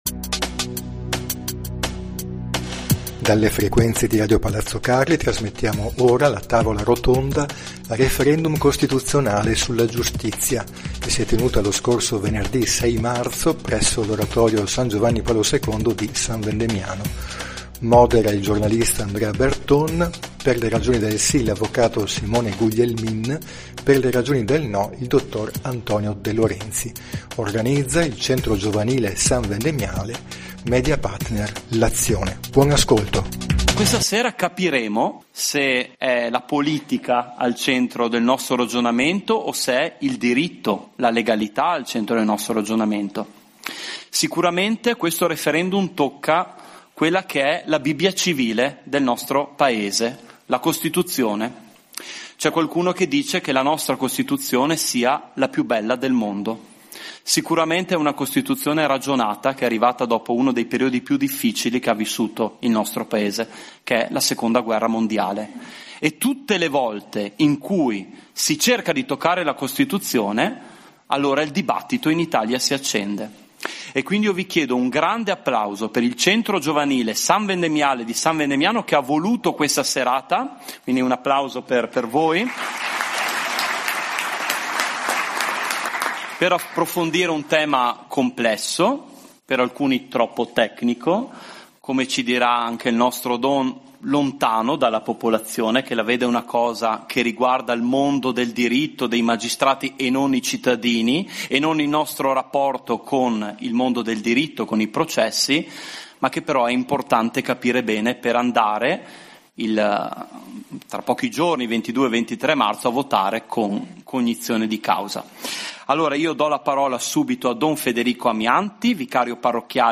HOME PAGE PODCAST Live del 19/4/2015 Gli Speciali di RPC sono documenti sonori registrati in varie occasioni culturali della provincia e non quali convegni, interviste, ecc.
Tavola rotonda